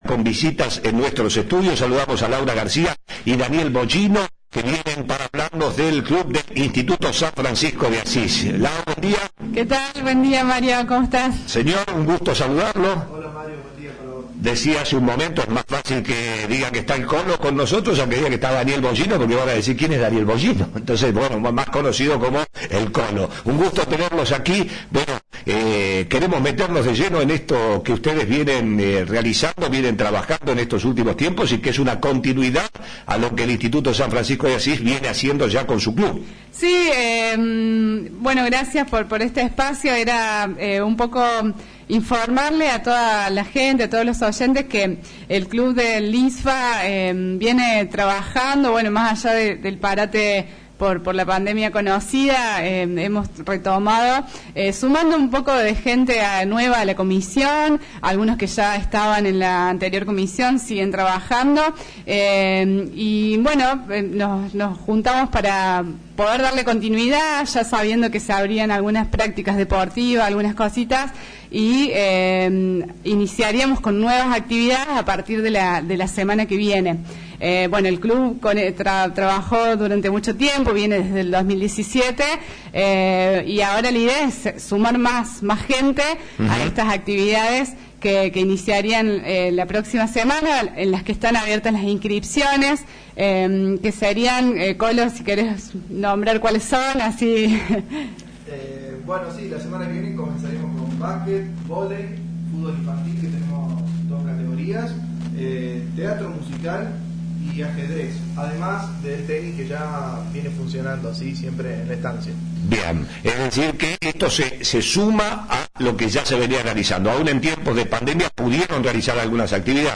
visitaron nuestros estudios